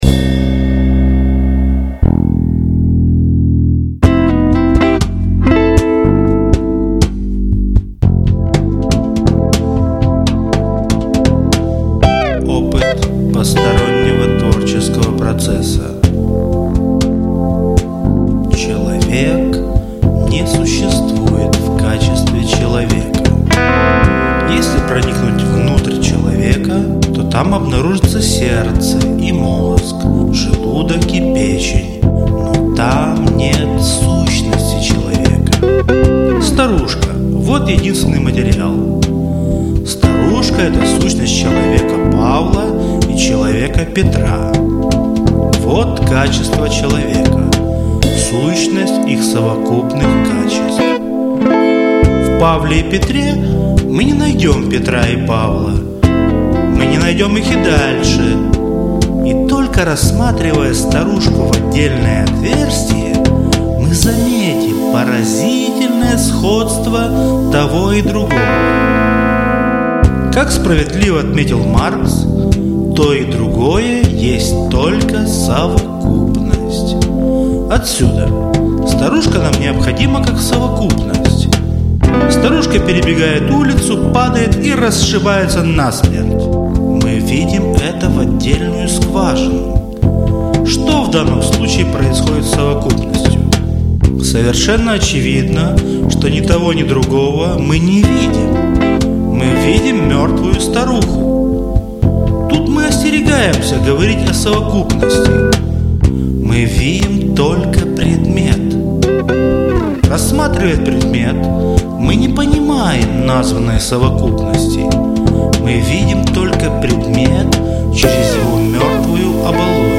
• Жанр: Декламация